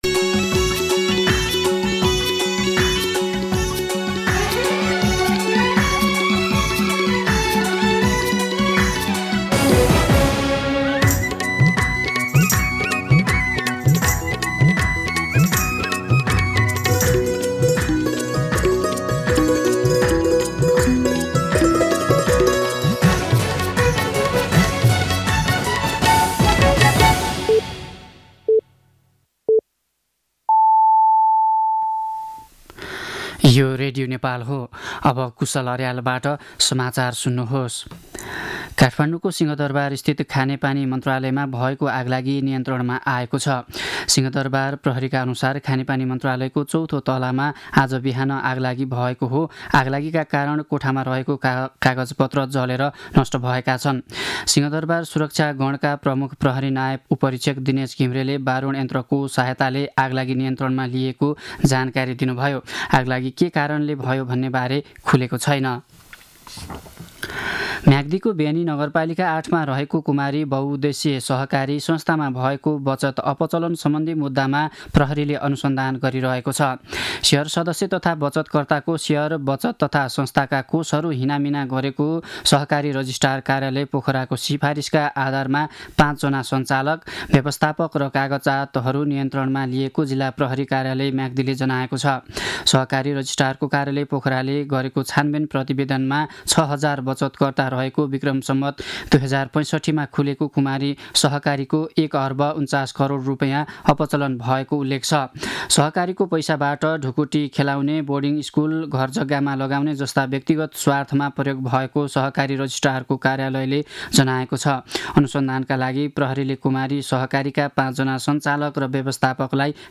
मध्यान्ह १२ बजेको नेपाली समाचार : २७ माघ , २०८१
12pm-News-26-.mp3